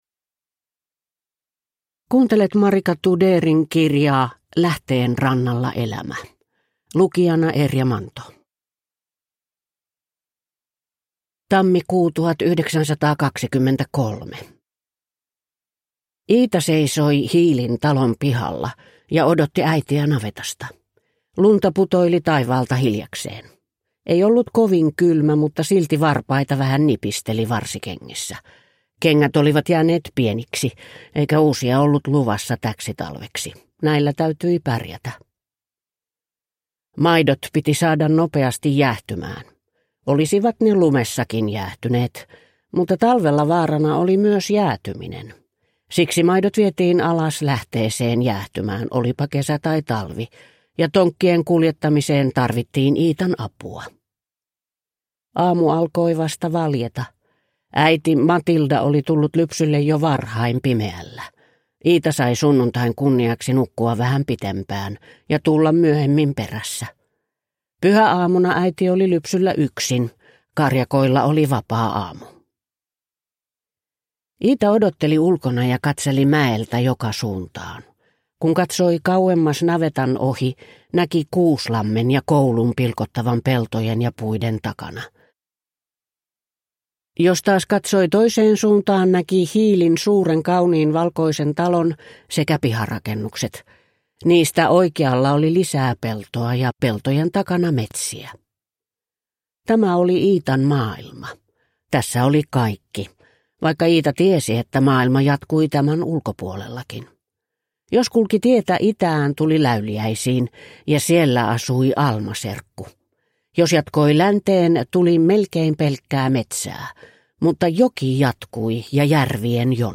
Lähteen rannalla elämä (ljudbok) av Marika Tudeer